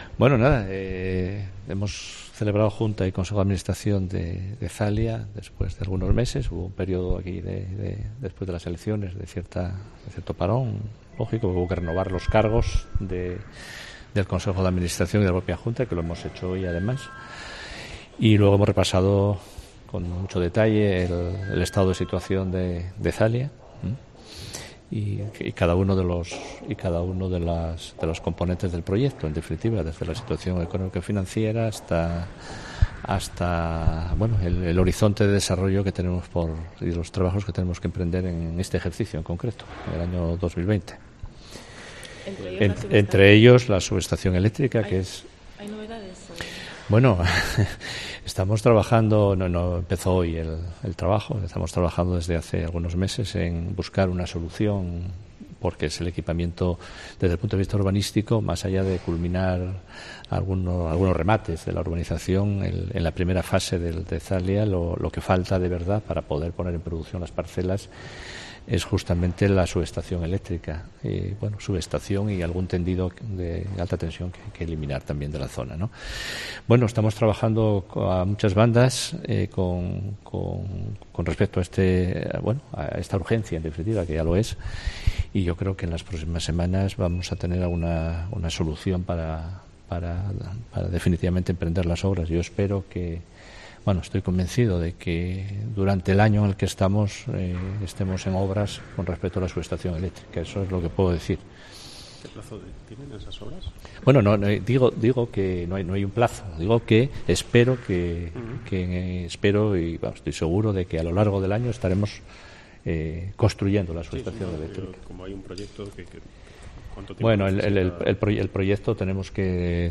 Juan Cofiño (Consejero de Infraestructuras) habla de la ZALIA